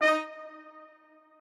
strings8_35.ogg